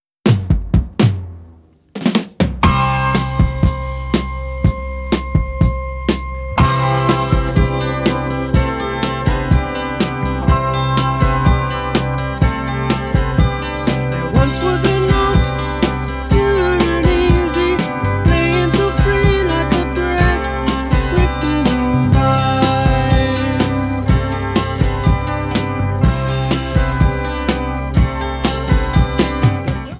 The 2006 CDs sound a bit louder.